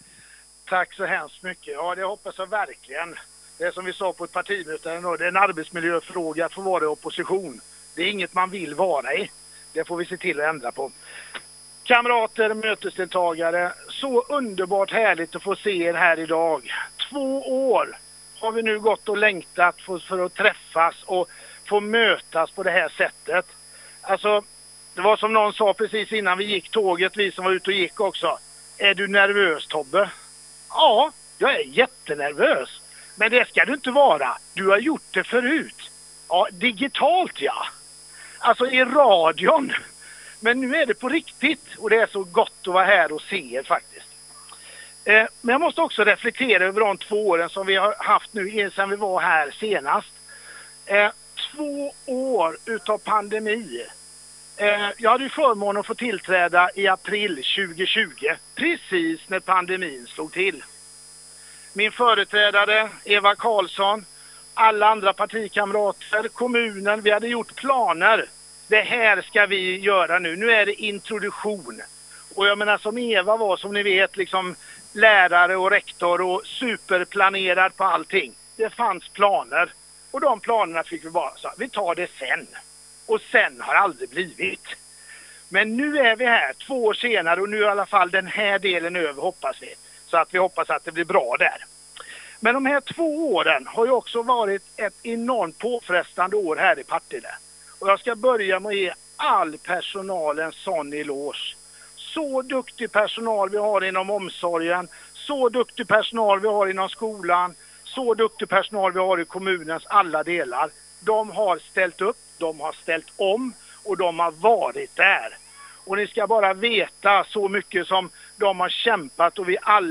Första-maj-tal Partille 2022
Här finns nu talen tillgängliga från första-maj-firandet i Partille 2022